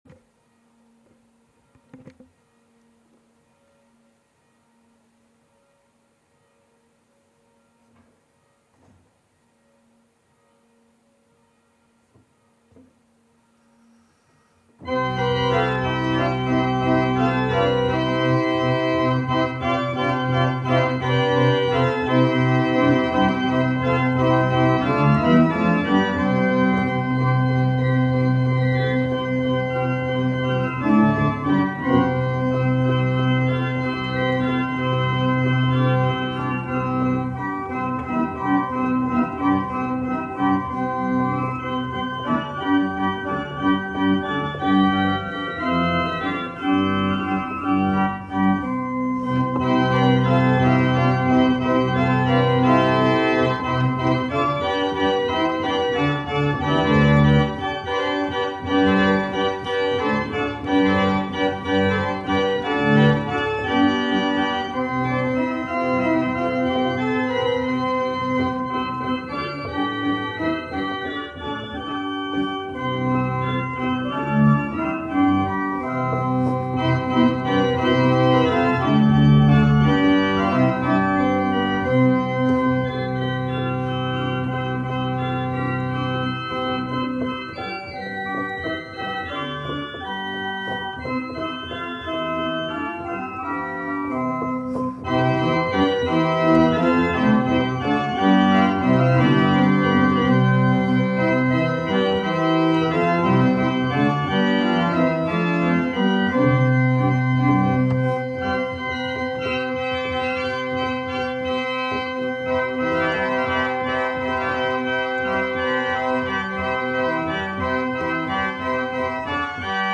aus der Kirche Mittelherwigsdorf